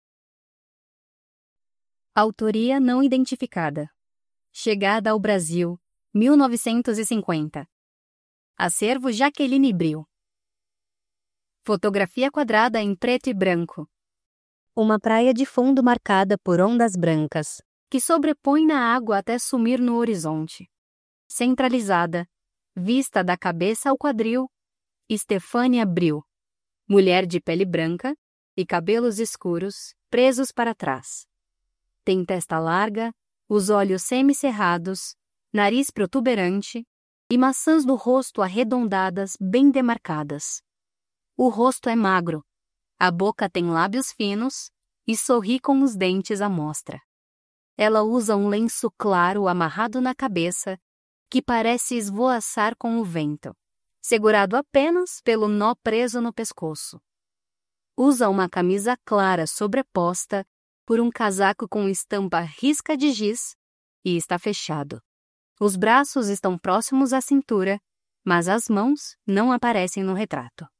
Audiodescrições